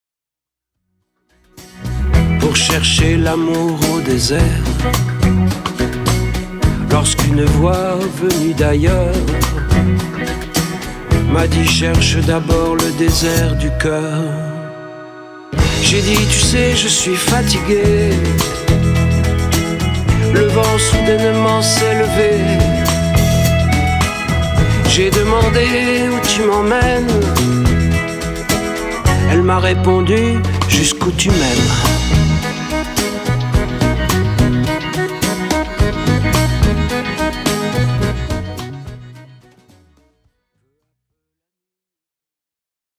Sa voix qui chante et déclame nous interpelle.